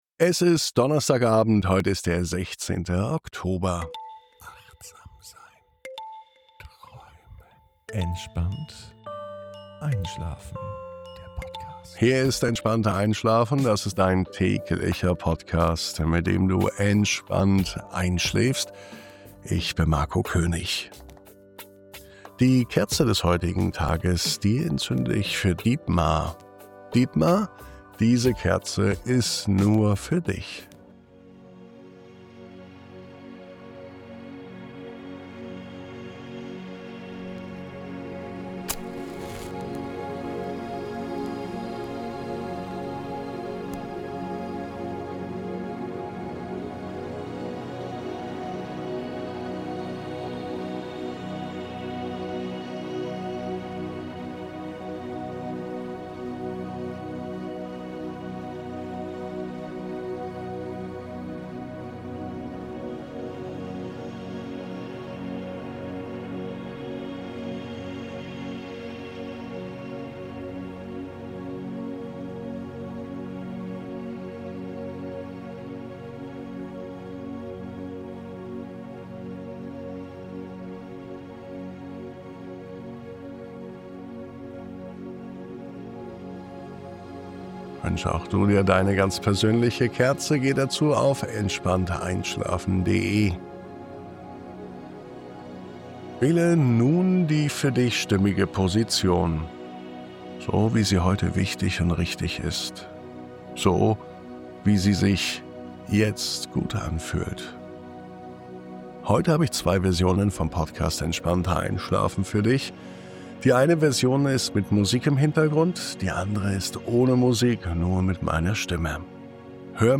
Diese Traumreise begleitet dich genau dorthin...